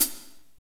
HAT P B PH03.wav